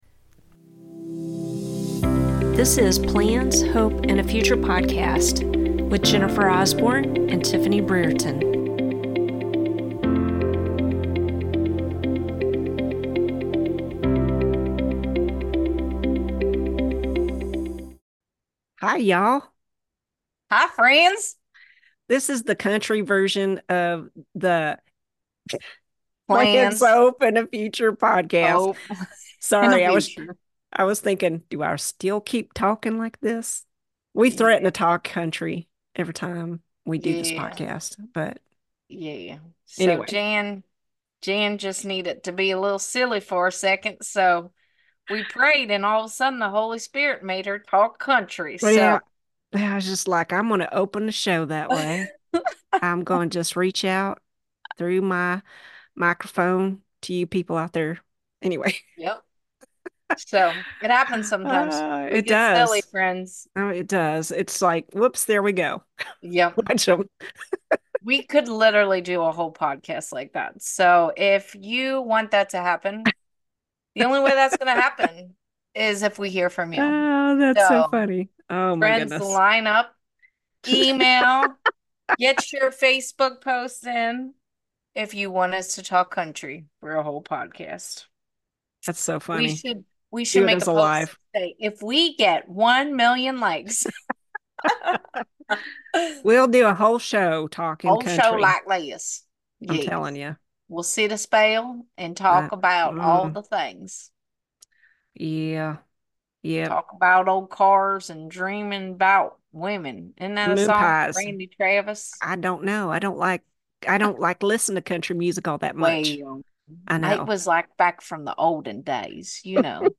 The earth is covered in the splendor of the Lord and the stars, sun, and moon declare his majesty. We talk about that, and...we do some of it with a country accent.